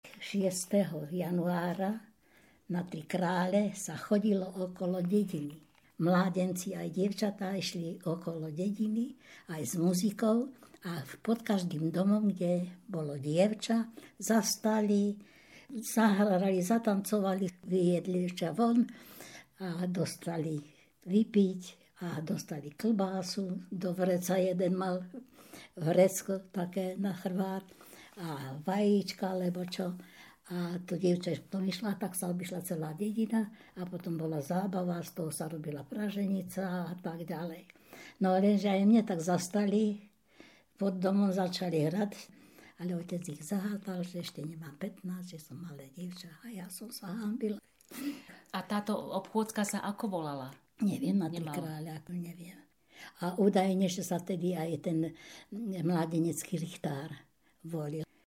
Place of capture Hriňová